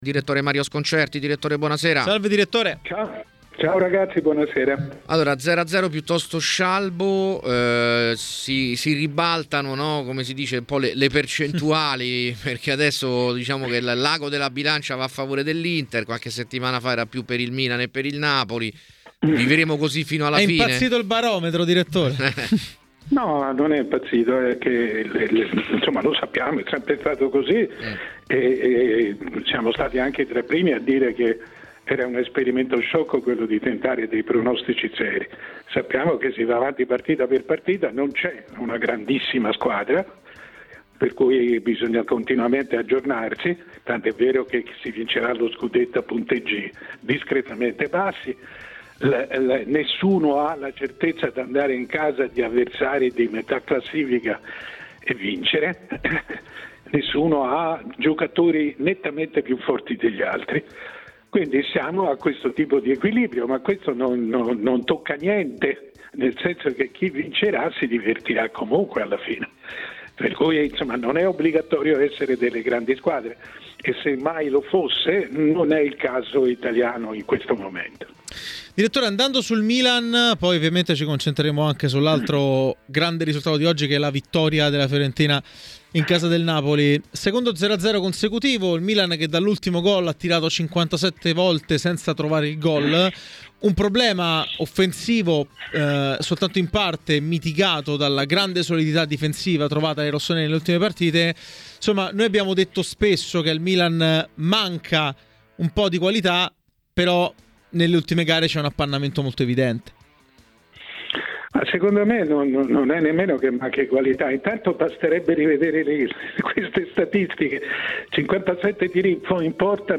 Ascolta l'audio Per commentare la giornata di Serie A, a Tmw Radio è intervenuto il direttore Mario Sconcerti .